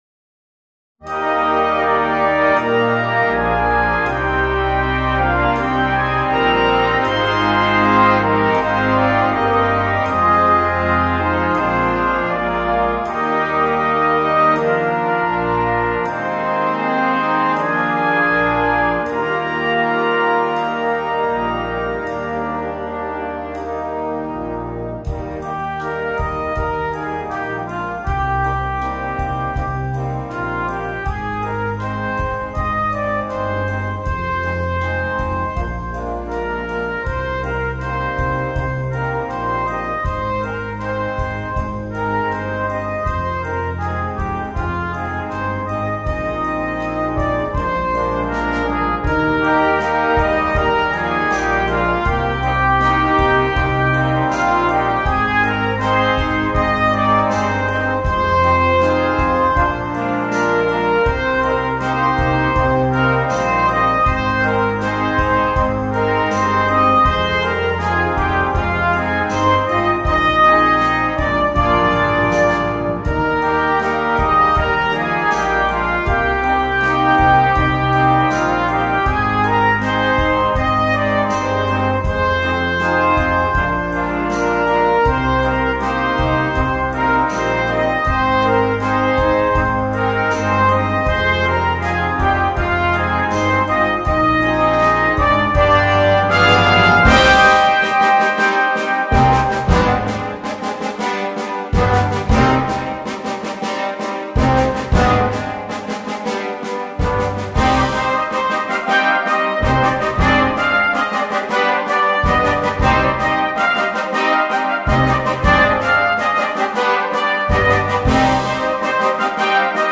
2. Brass Band
sans instrument solo
Musique légère